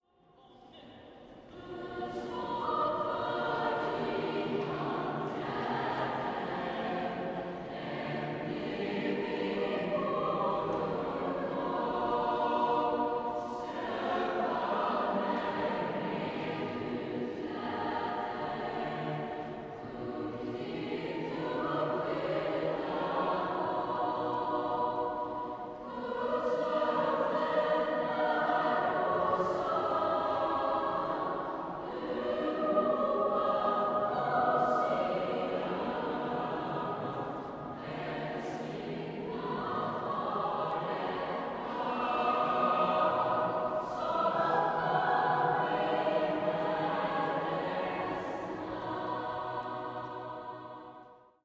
Dans la cathédrale, une chorale prépare la messe de Noël.